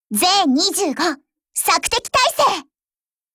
Cv-40125_warcry.wav